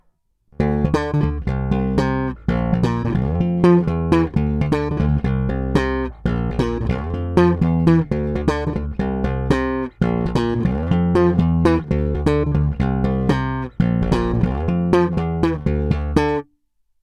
Následující zvukové ukázky jsou provedeny přímo do zvukové karty a kromě normalizace ponechány bez jakýchkoli úprav. Použité jsou neznámé niklové struny ze sady 45-105 ve slušném stavu.
Slap